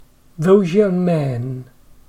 /z/ (followed by /j/) becomes /ʒ/